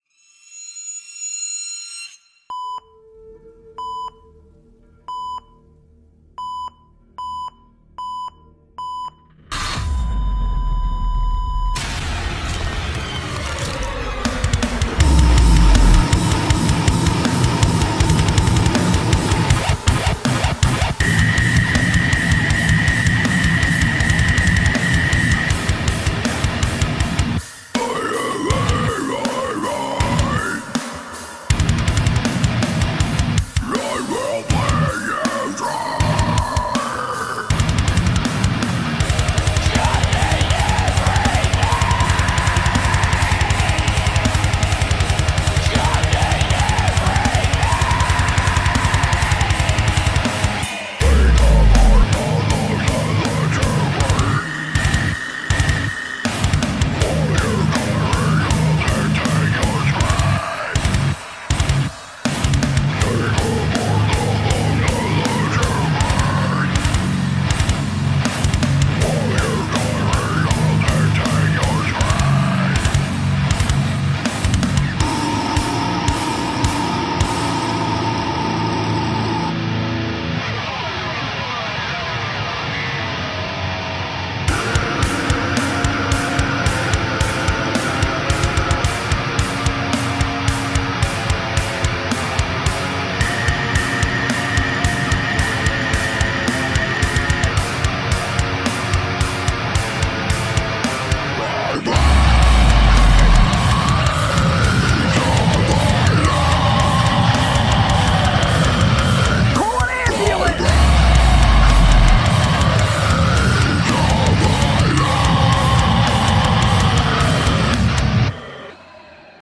Need Mix and master for slamming deathcore with hip hop and electronic influences